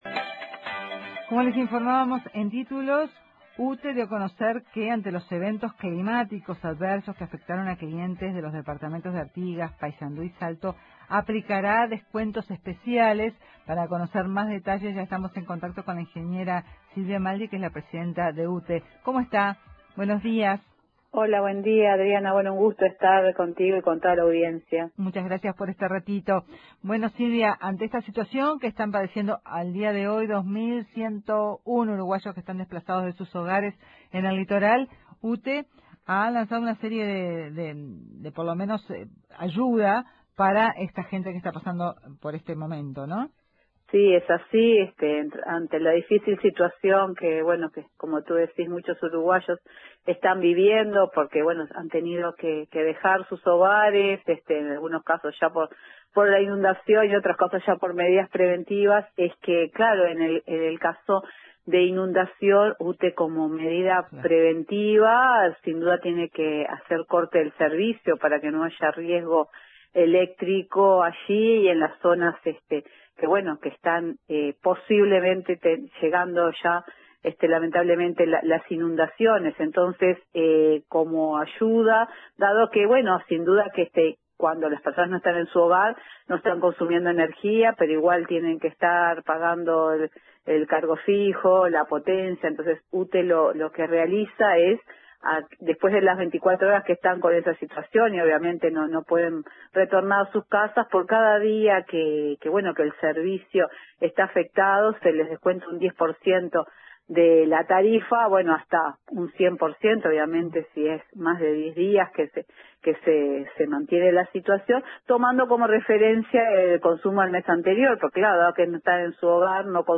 Silvia Emaldi, presidenta de Ute, dijo a Informativo Uruguay que “ante una situación de inundaciones, la empresa tiene que cortar el servicio como medida preventiva y como forma de ayuda a los desplazados, se realizará un descuento del 10% de la tarifa por cada día de interrupción del servicio por todos los conceptos energéticos (Cargo Fijo, Cargo por Potencia y Cargo por Energía) hasta llegar a un 100%, en función de la factura del mes anterior”.